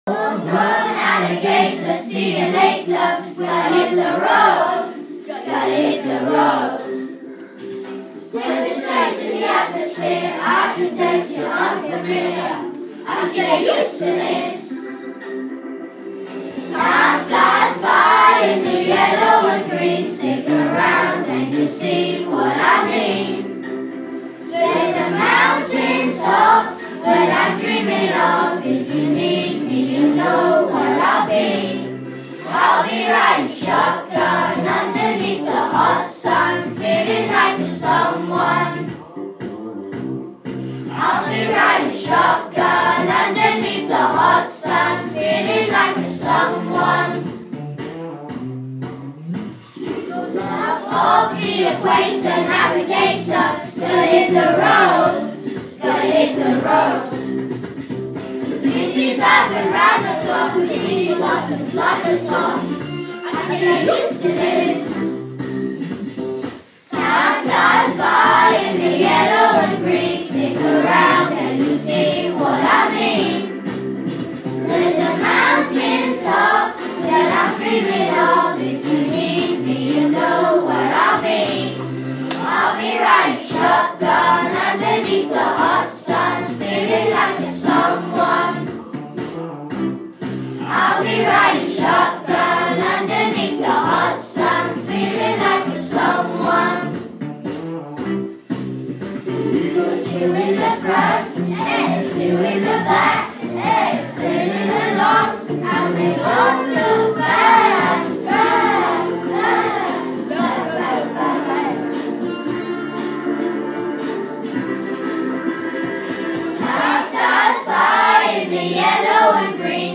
Year 3 singing Shotgun